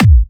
VEC3 Bassdrums Trance 66.wav